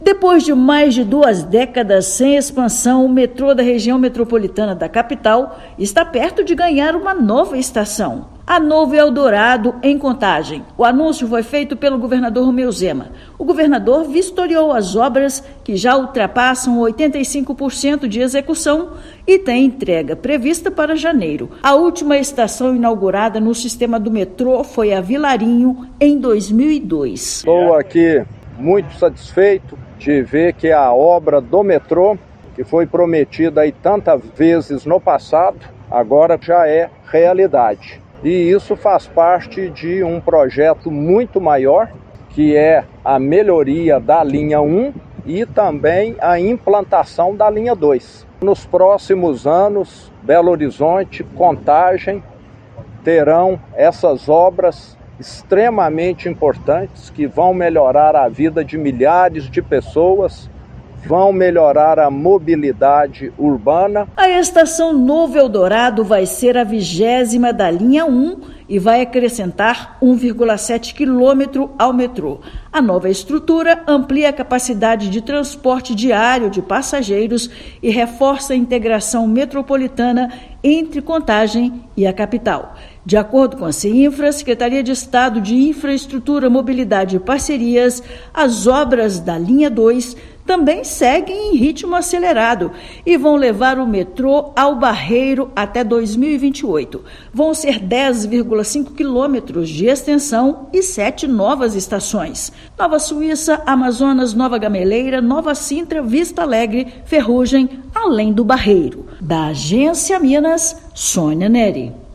Governador vistoriou, nesta terça-feira (7/10), as obras da Estação Novo Eldorado, que vai integrar Contagem a Belo Horizonte. Ouça matéria de rádio.